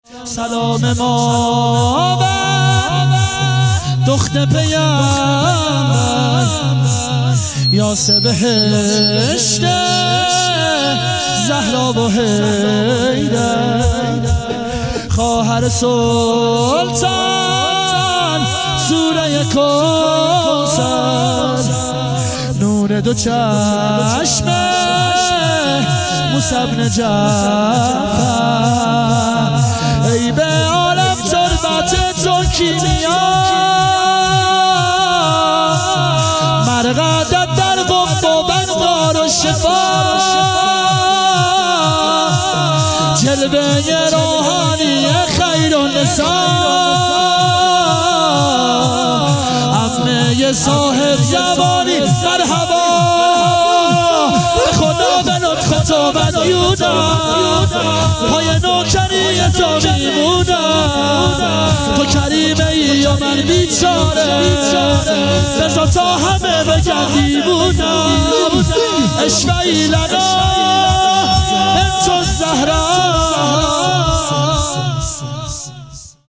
شهادت حضرت معصومه (س)